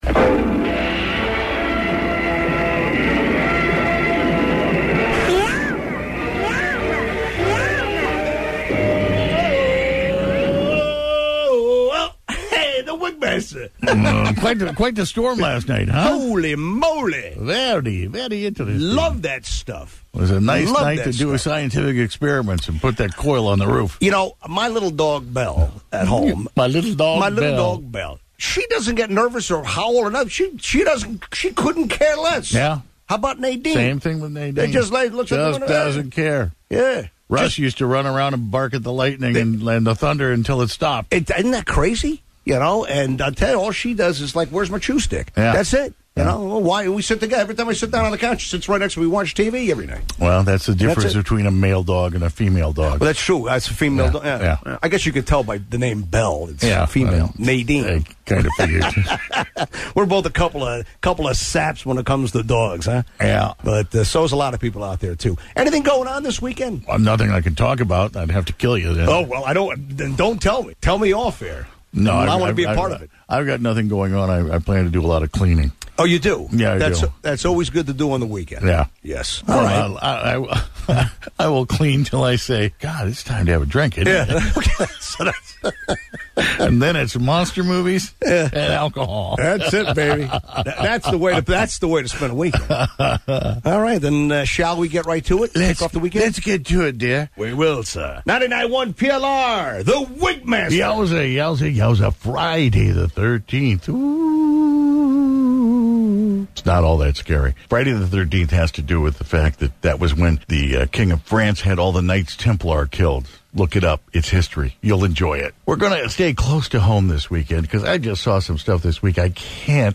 Live Wigout